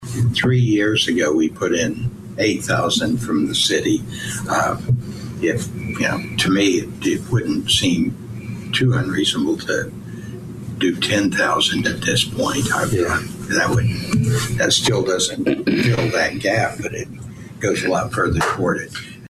Commissioner Tom Shaw